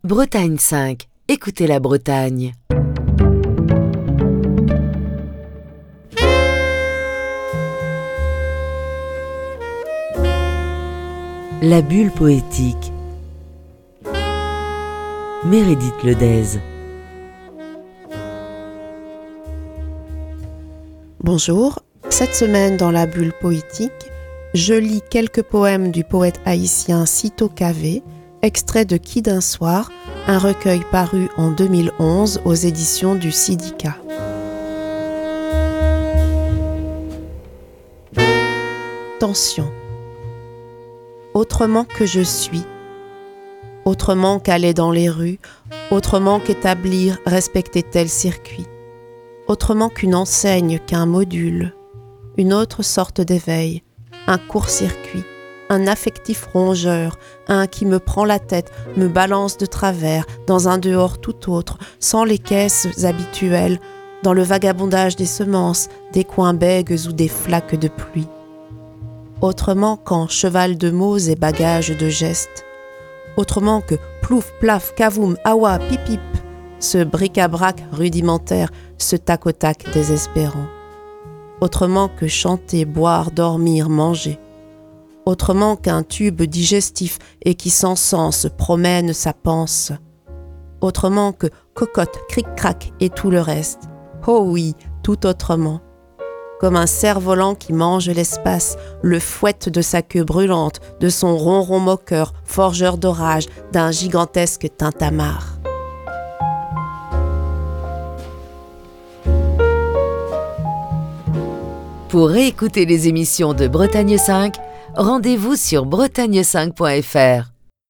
lit quelques poèmes du poète haïtien Syto Cavé